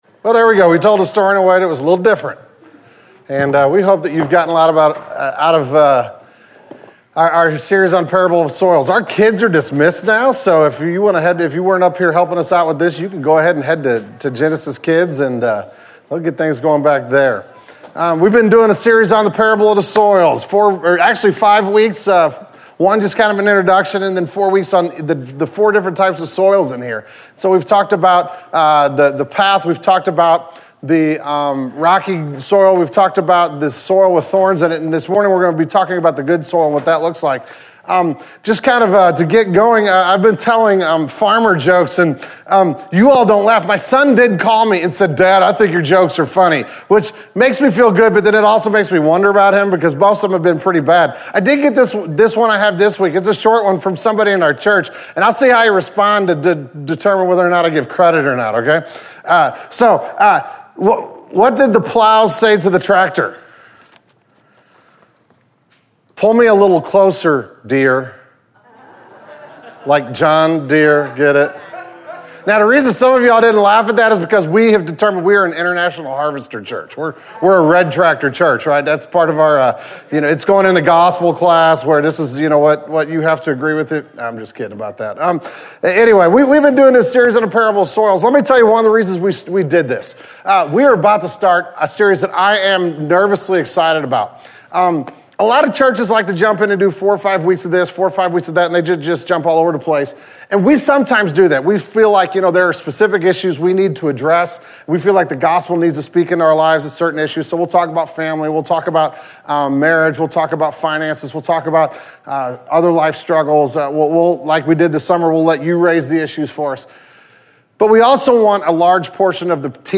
The October 2010 Sermon Audio archive of Genesis Church.